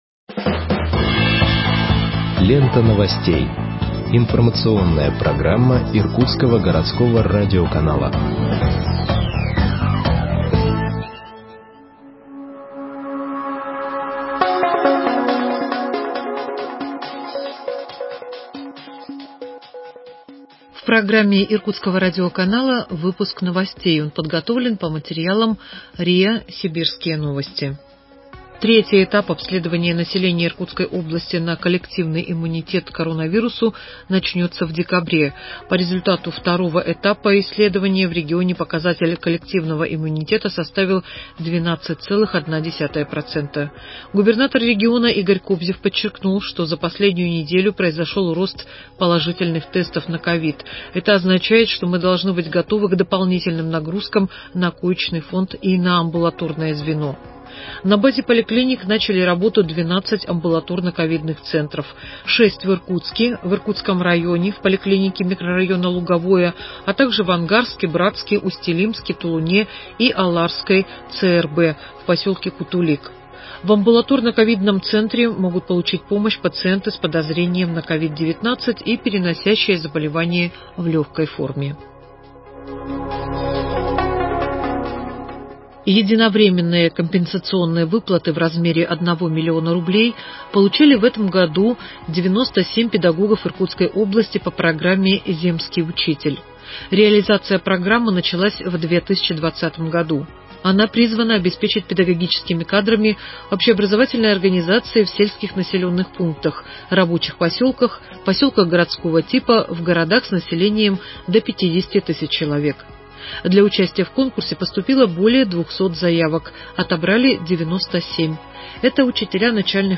Выпуск новостей в подкастах газеты Иркутск от 03.12.2020 № 1